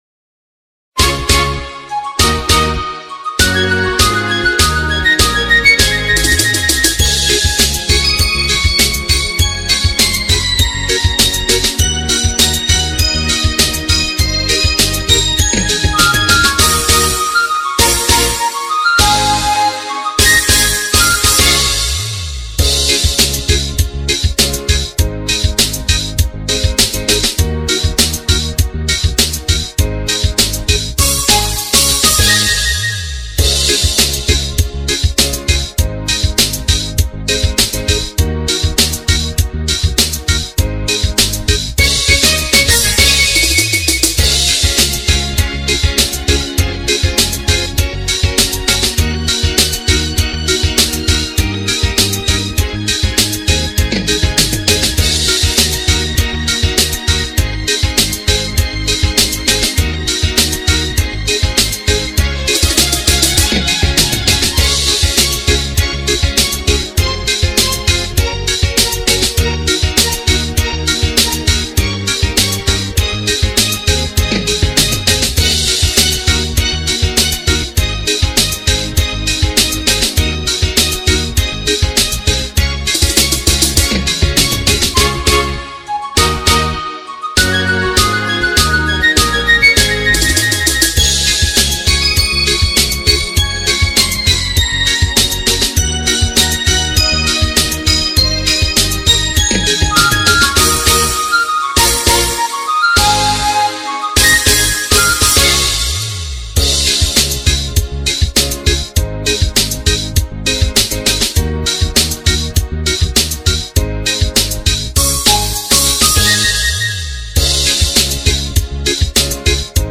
Bài hát tập 20/11 gv